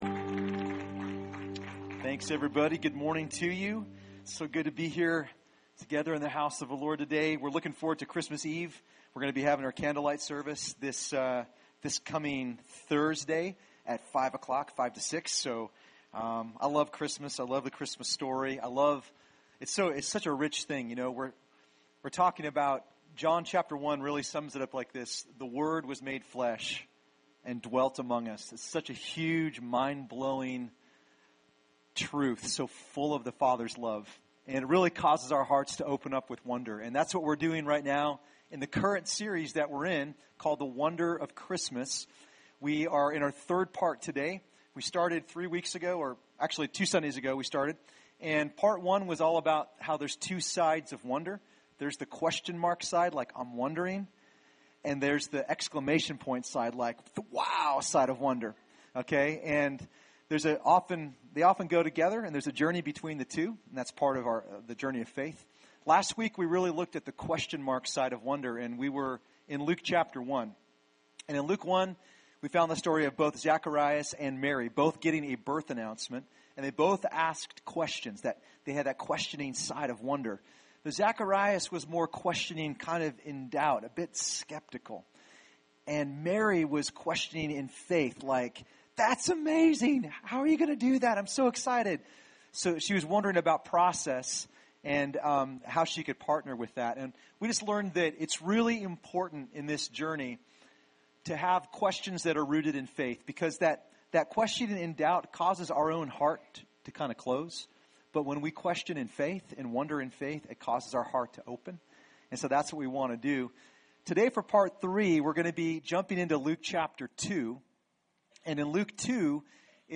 Recorded at New Life Christian Center, Sunday, Dec 20, 2015 at 11 AM.